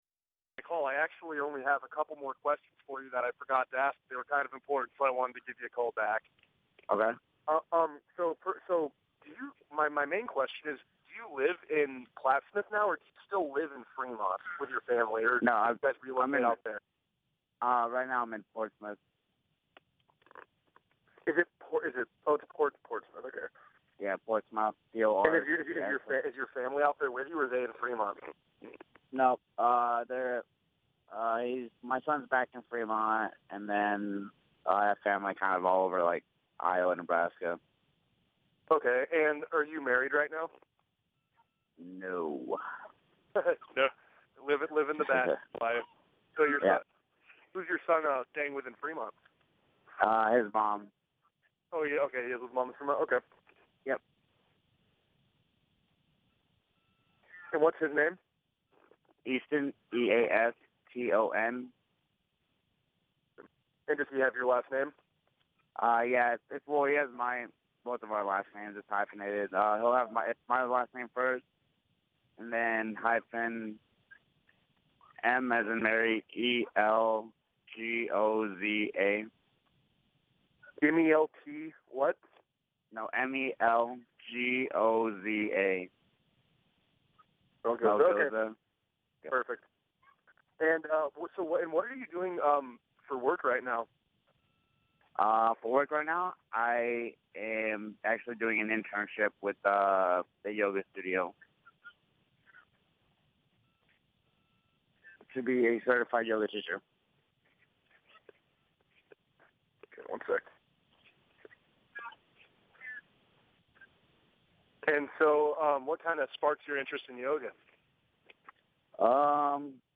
talks to a Fremont Tribune reporter about participating in the 2015 DoD Warrior Games.